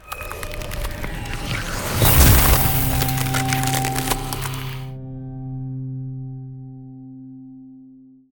heal.ogg